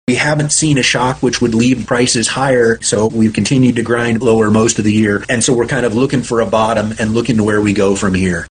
USDA Chief Economist Seth Meyer sees no immediate price shocks to rescue producers from another year of low prices in 2025. Meyer summed up the outlook for the new year this way.